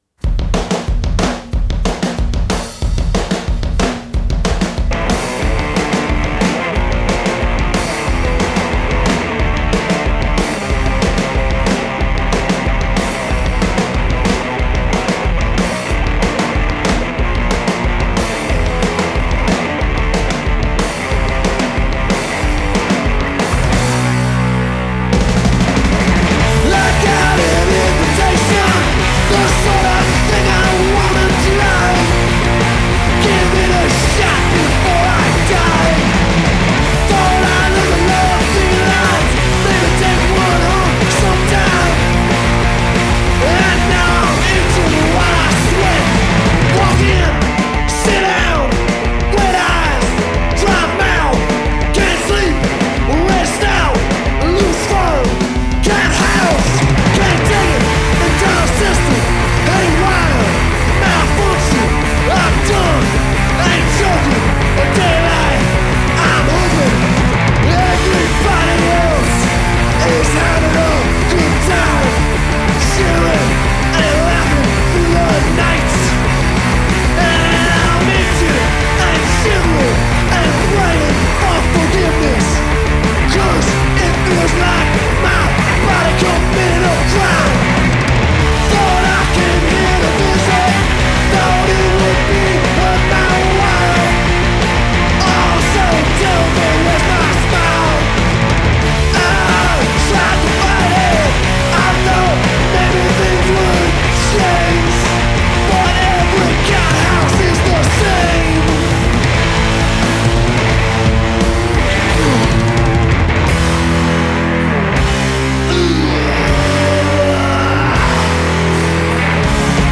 recorded right here in MAGNET’s hometown